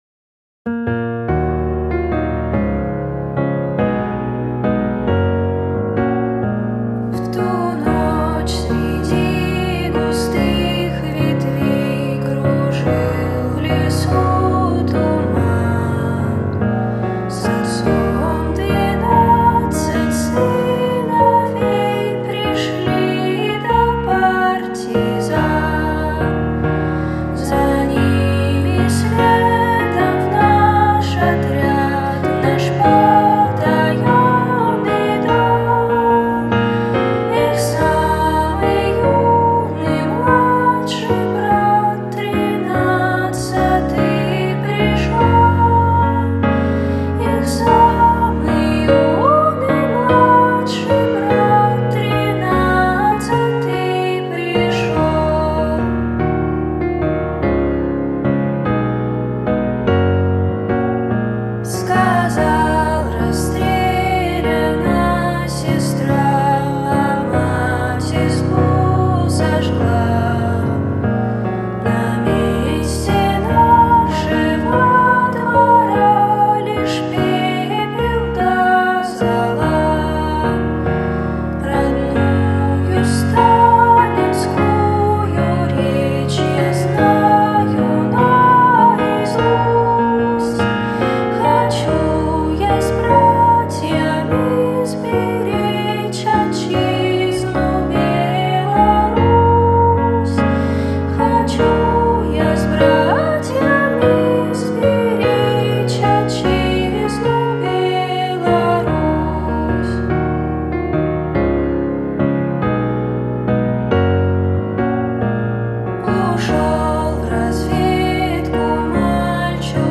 По моей личной просьбе ее записали друзья.